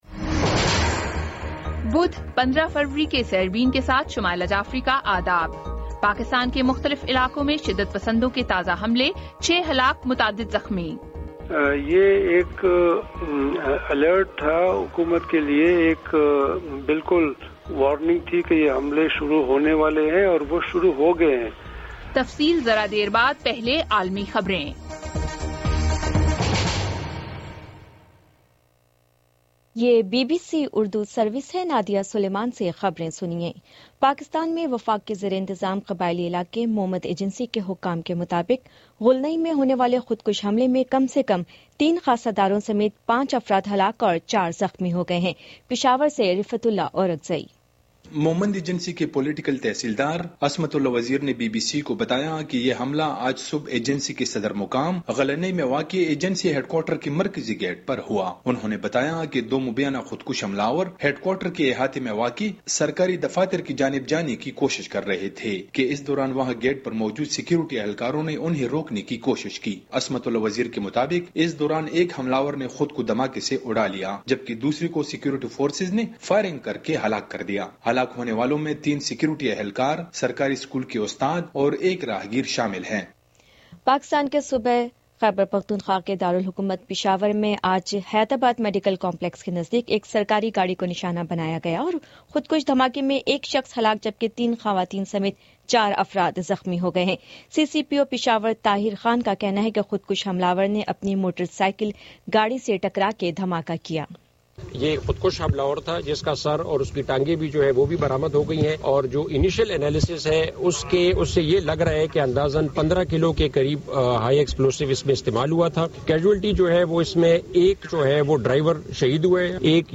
بدھ 15 فروری کا سیربین ریڈیو پروگرام